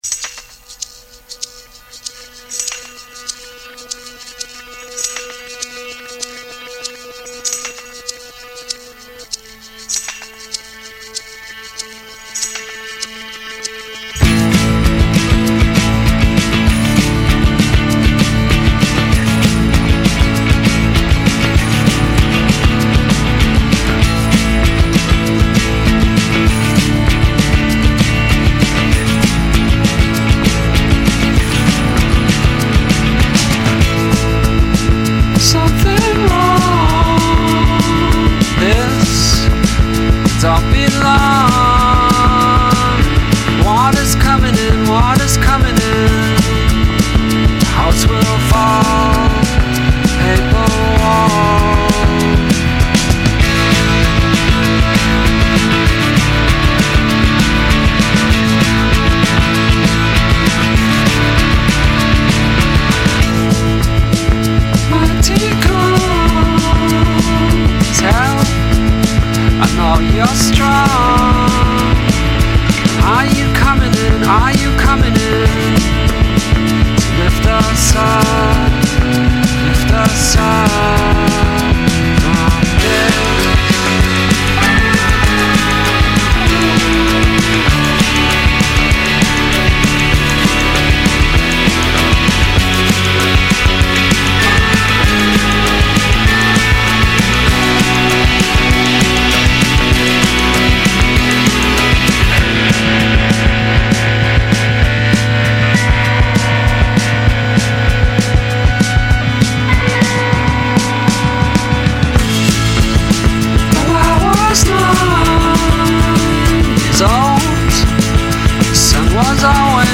ungewohnt zugänglich